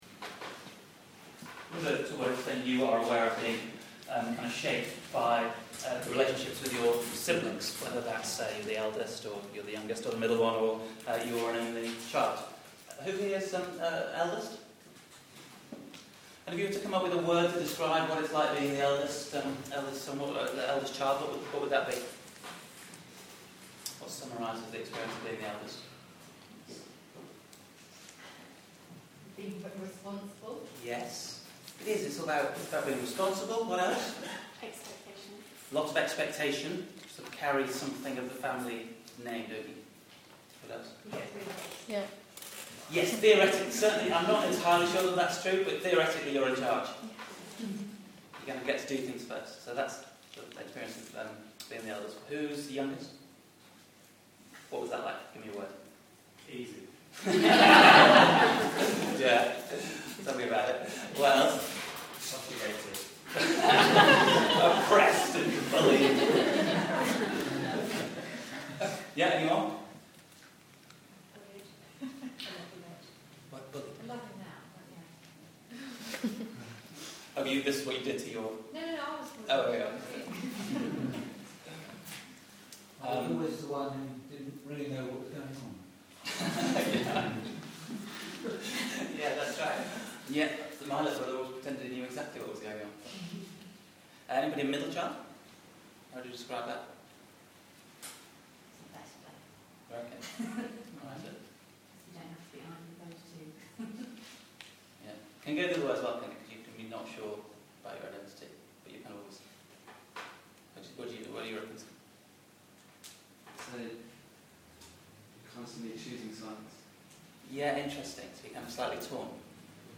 genesis-25-sermon-jacob-and-esau.mp3